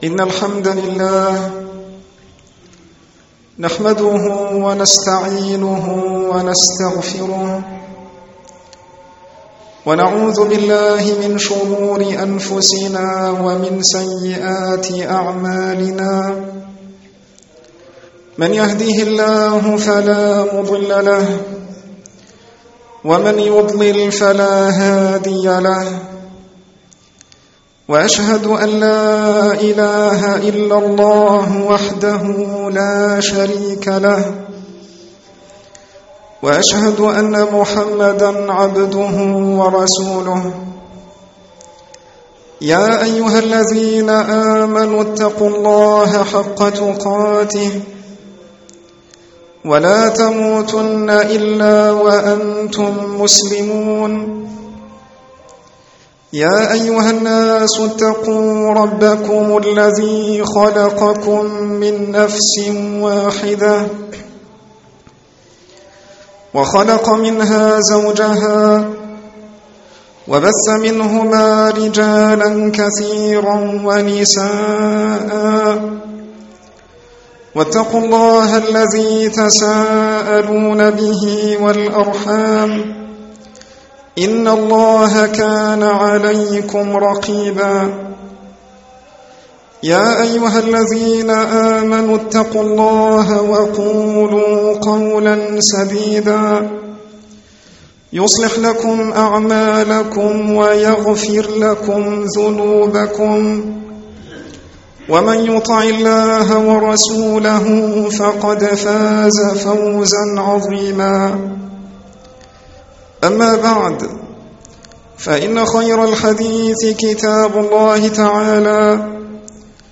خطب عامة